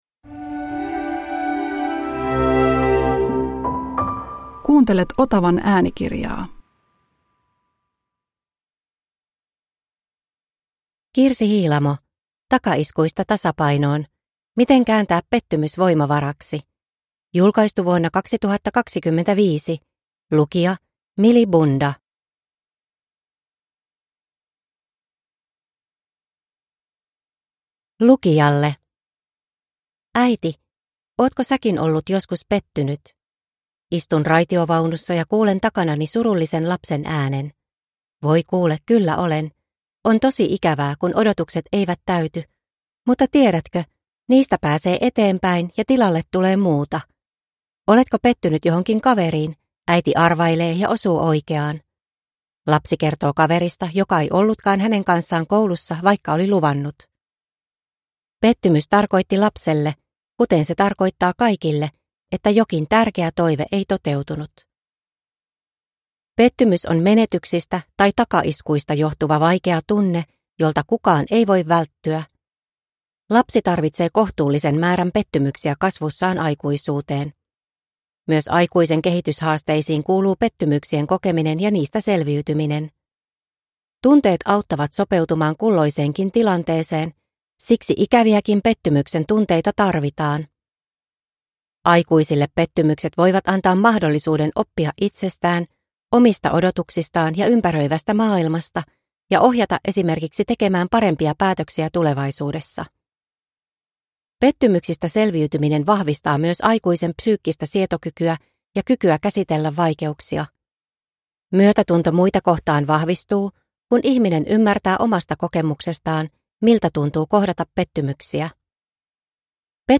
Takaiskuista tasapainoon (ljudbok